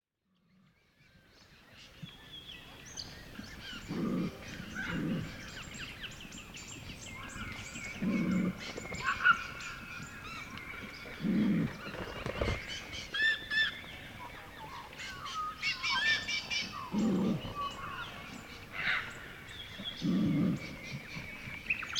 Звуки кенгуру
Шум схватки австралийских кенгуру в западных лесах